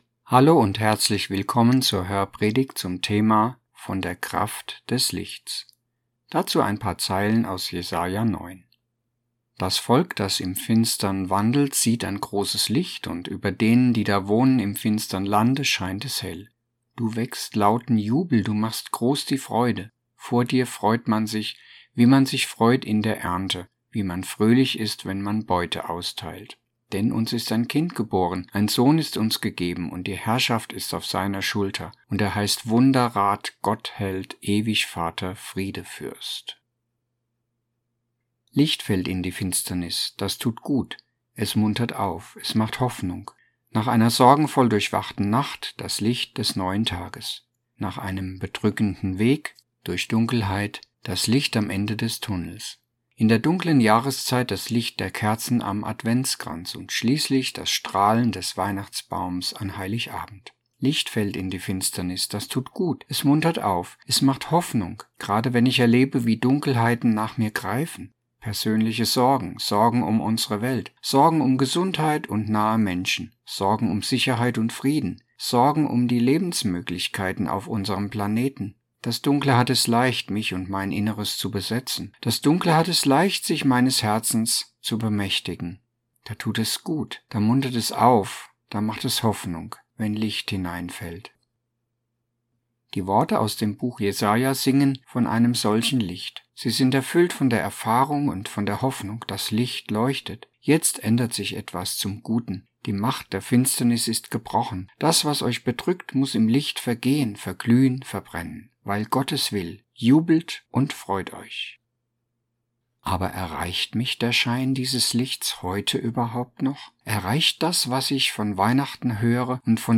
Hörpredigt-ZV-Weihnachten_2025.mp3